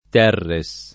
[ t $ rre S ]